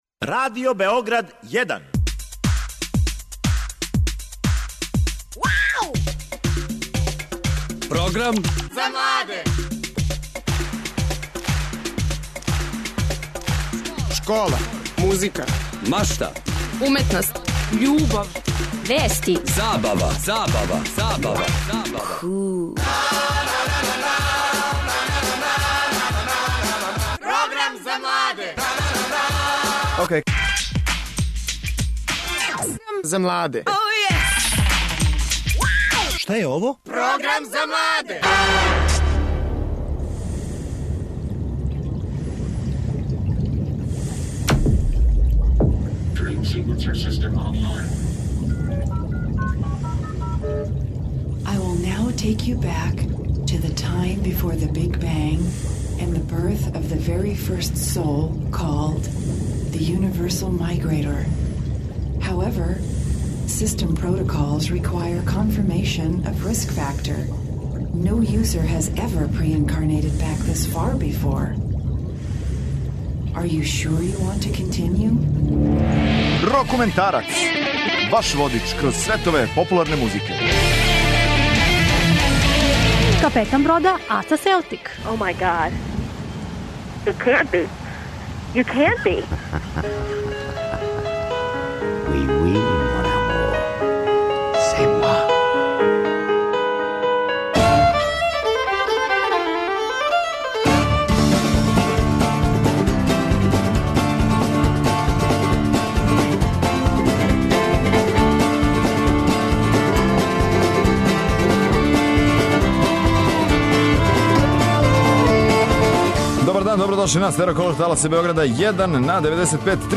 Специјал са Beer festa! У емисији ћете чути ексклузивни интервју са Simple Minds као и интервју са Бором Ђорђевићем. Наравно, као и сваког петка, представићемо вам нове албуме са светске и домаће сцене.